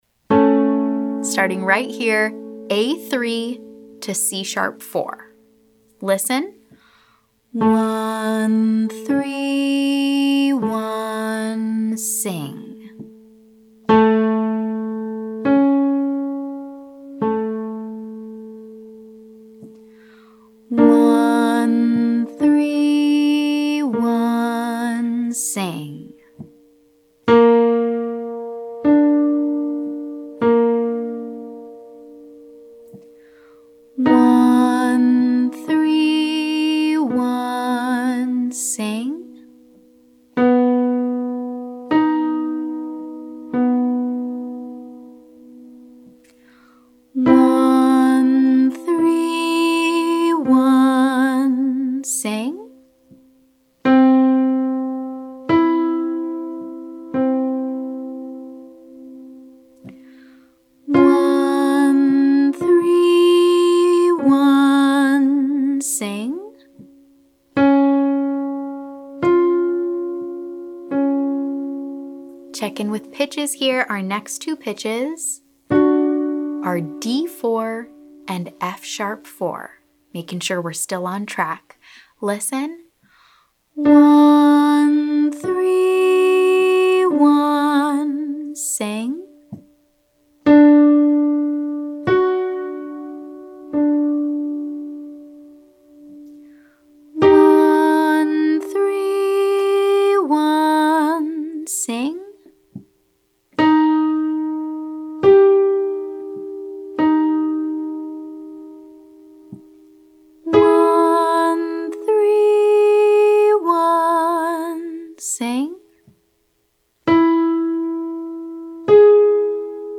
For this first exercise, I’ll play a major third so you can listen and audiate, or sing in your head; then, I’ll play a second time for you to sing along.